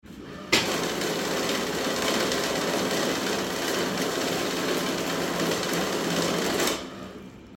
Money Counter Machine Sound Effect
Counting paper banknotes with a money counting machine at a bank. Money sounds. Office sounds.
Money-counter-machine-sound-effect.mp3